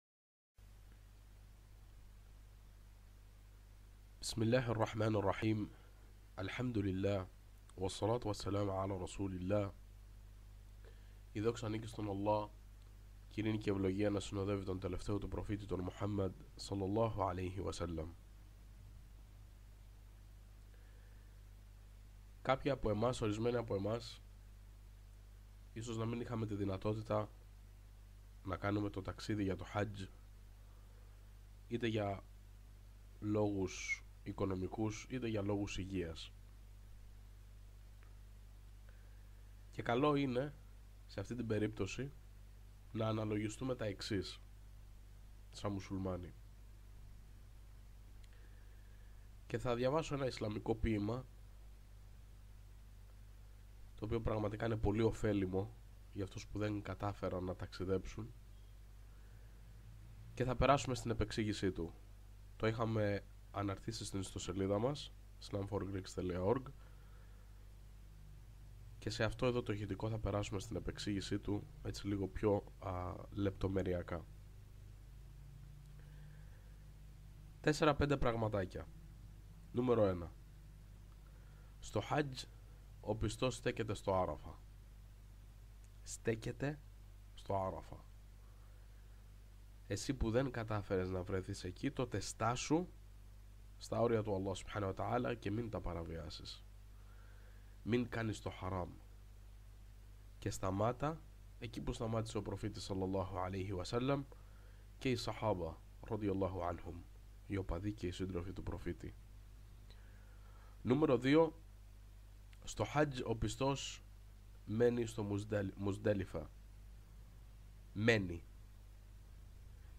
Όμως τι θα μπορούσε να κάνει εναλλακτικά και γενικότερα ποια είναι η ουσία και ο συμβολισμός αυτού του ιερού προσκυνήματος. Στην παρακάτω ομιλία/παρουσίαση αναλύεται ένα εξαιρετικό ποίημα του μεγάλου λόγιου και Ιμάμη του Ιμπν Ράτζαμπ το οποιο αφορά τον κάθε ένα από εμάς που δεν κατάφερε να πραγματοποιήσει το Χάτζ.